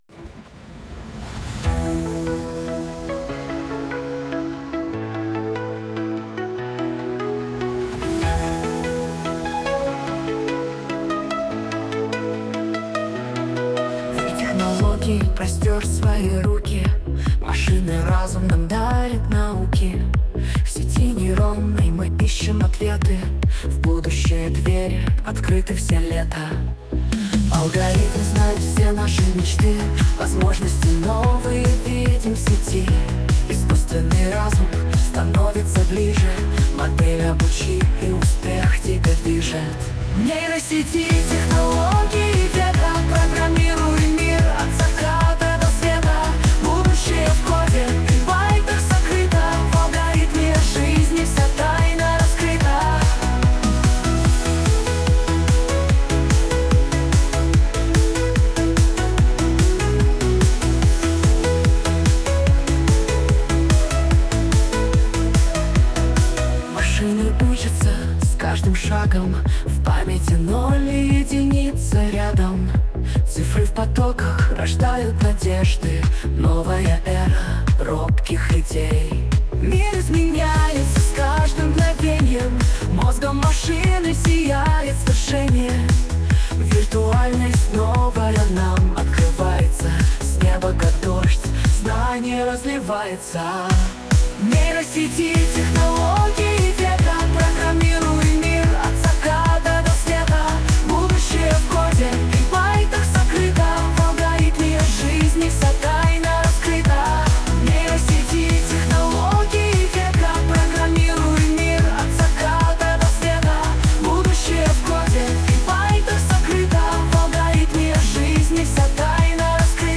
Песня про майнор